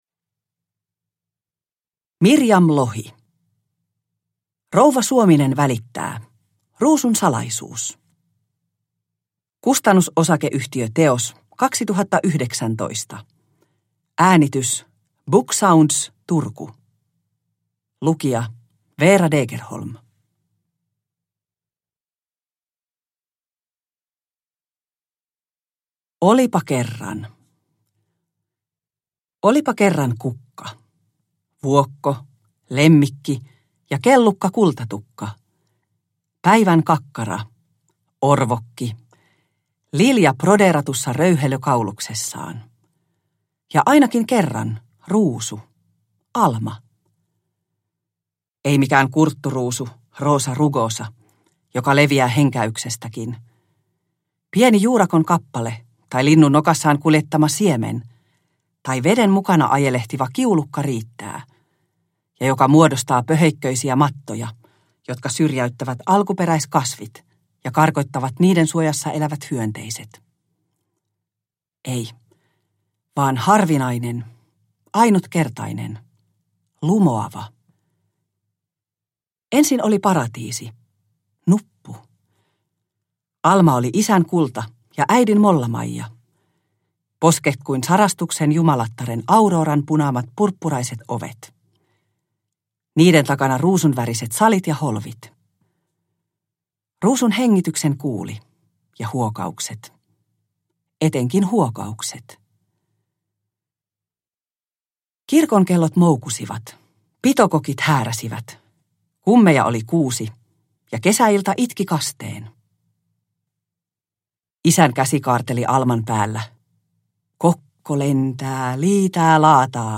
Ruusun salaisuus – Ljudbok